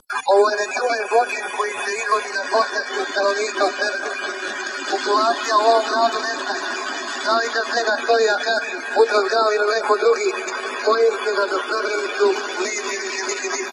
lošim radioamaterskim vezama uputio je apel za pomoć.